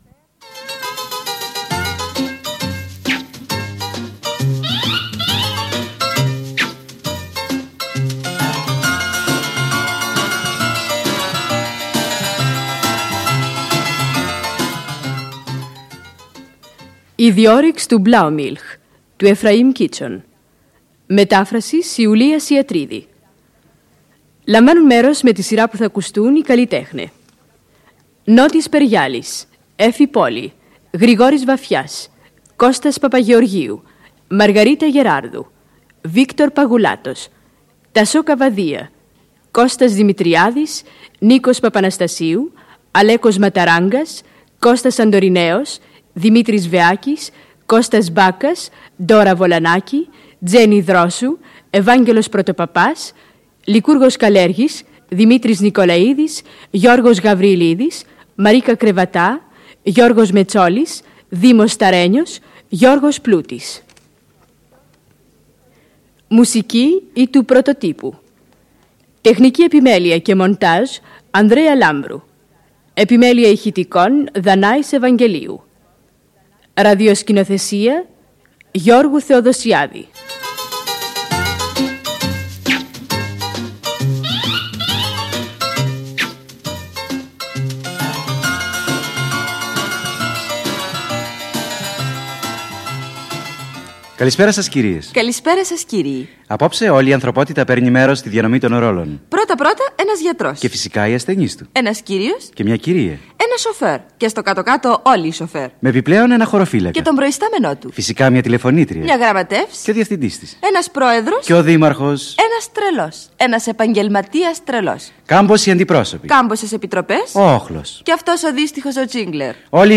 ραδιοφωνικά θεατρικά έργα